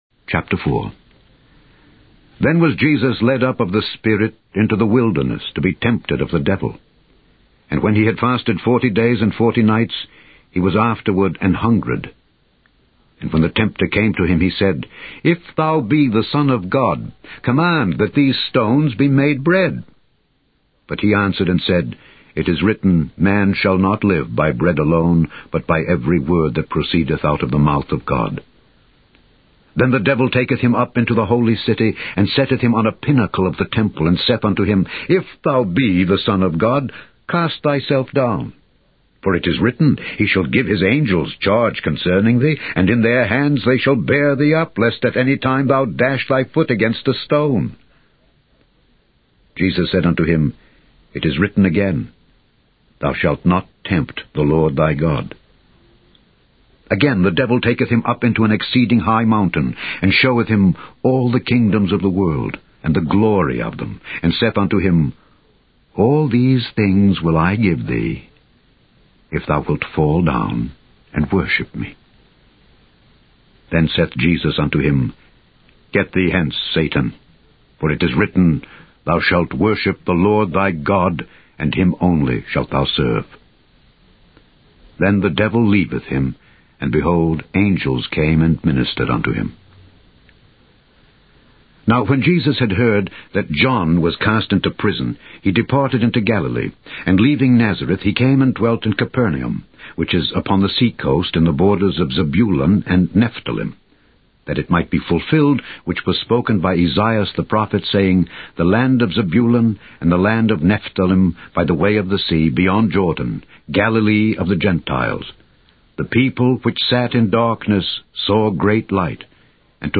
Directory Listing of ./Audio Books/The Holy Bible - Audio Bible - King James Version - Alexander Scourby - Voice of The Bible/ (SpiritMaji Files)